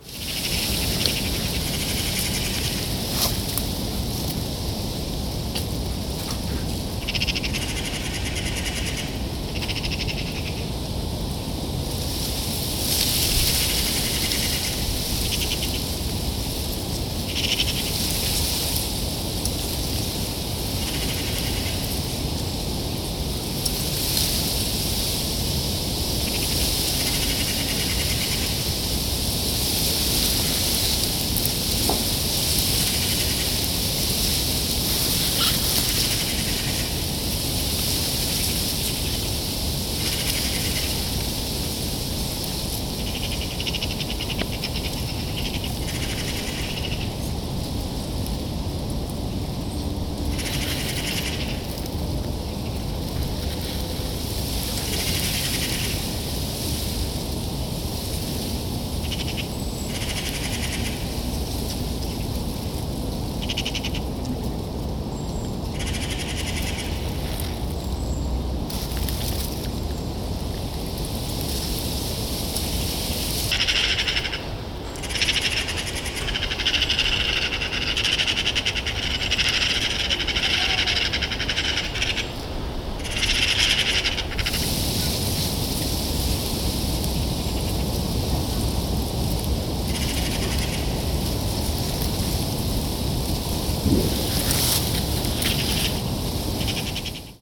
novyrokstraky.mp3